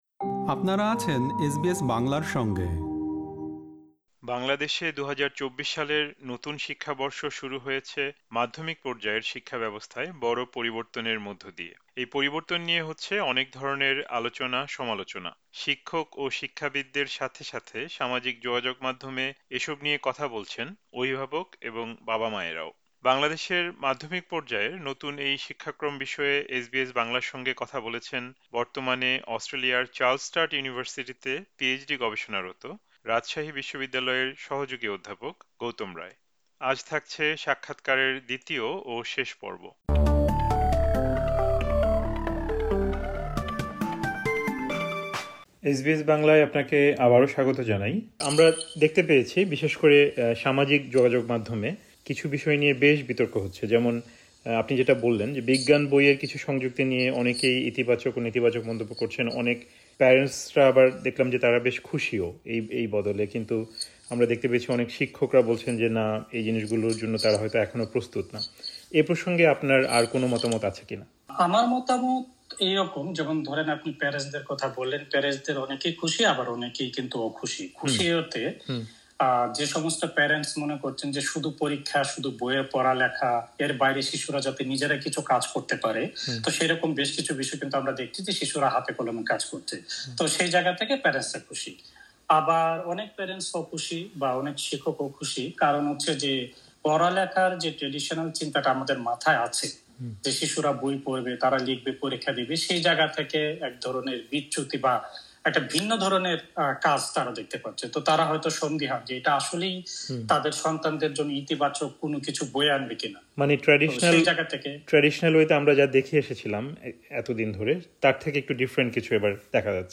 এখানে থাকছে সাক্ষাৎকারের দ্বিতীয় ও শেষ পর্ব।